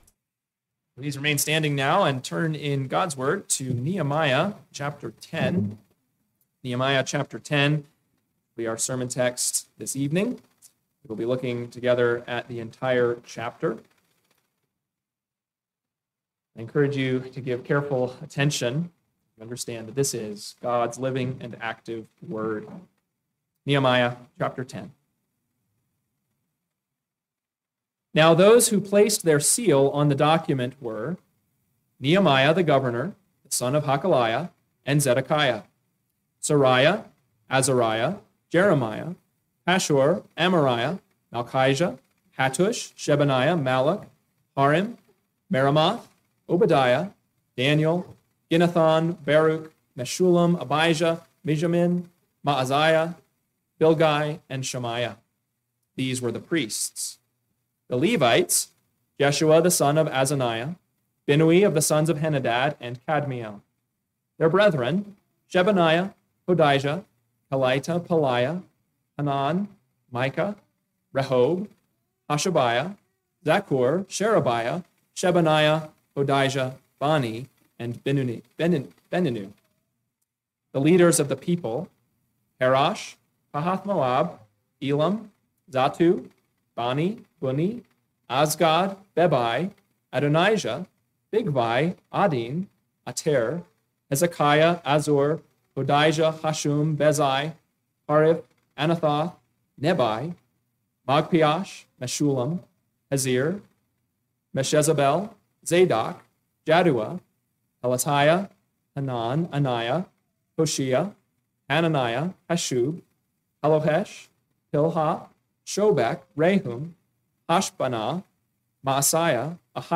PM Sermon – 10/26/2025 – Nehemiah 10 – Northwoods Sermons